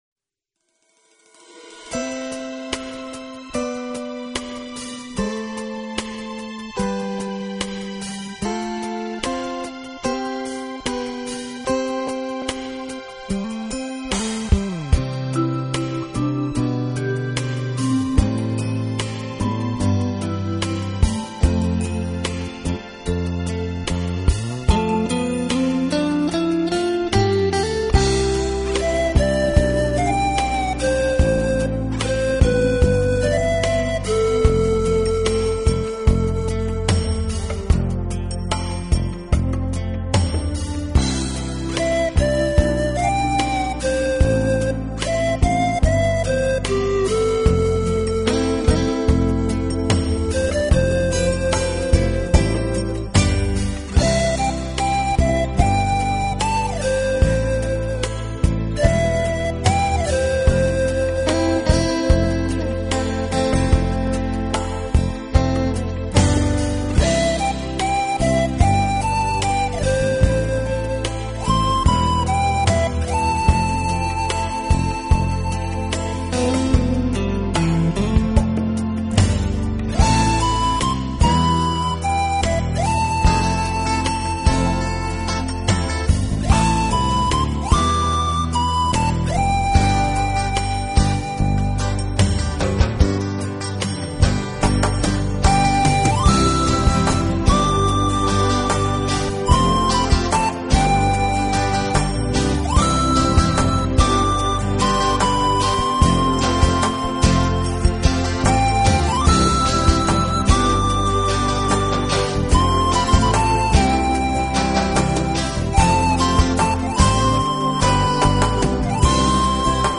音质而风靡全国，独具一格的空灵乐风，让聆听者随之倾倒。
引导你远离尘世的喧嚣，亲近浩瀚的大自然。微风，细雨，虫
鸣和奔涌的海浪声，把你引向一个未曾体验过的世界，美妙的